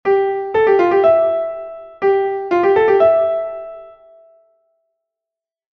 Grupeto de 4 notas
O grupeto será de catro notas cando o signo vaia entre dúas notas diferentes ou cando vai precedido por un mordente.